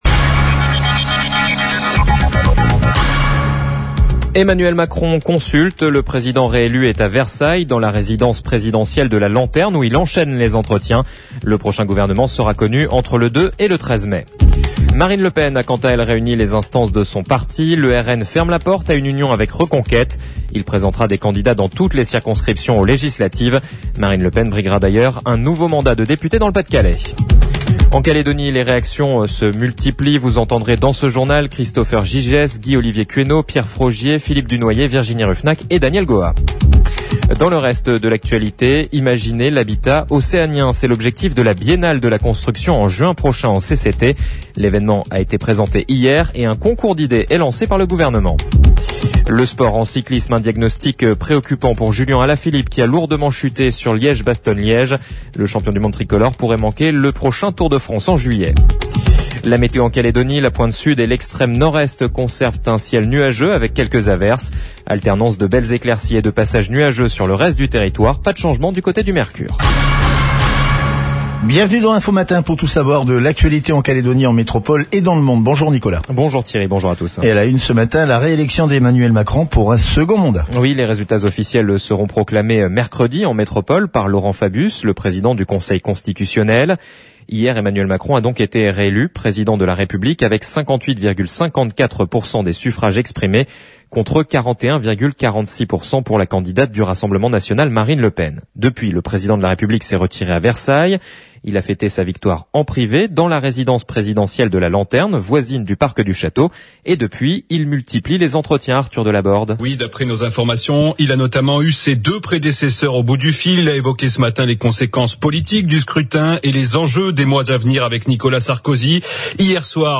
JOURNAL : MARDI 26/04/22 (MATIN)